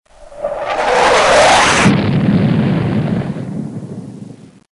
战斗机呼啸而过－YS070523.mp3
通用动作/06交通工具/普通飞行行器类/战斗机呼啸而过－YS070523.mp3
• 声道 立體聲 (2ch)